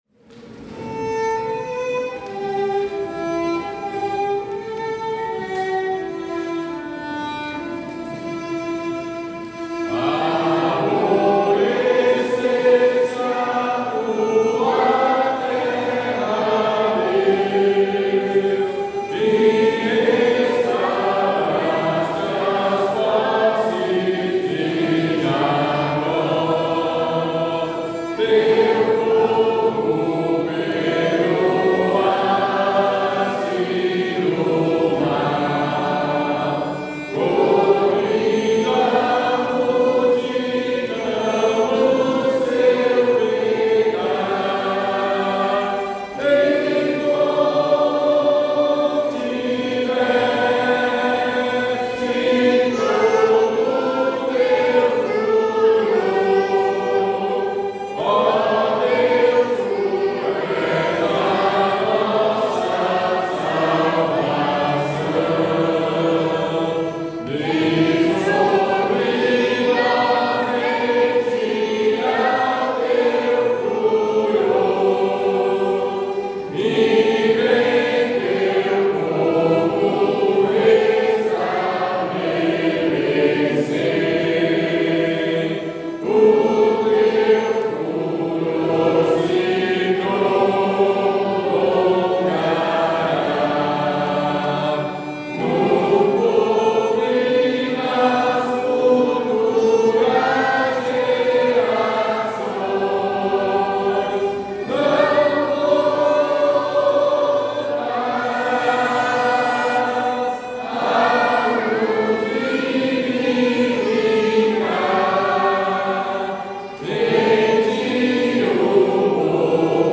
Melodia francesa, século XV
salmo_85B_cantado.mp3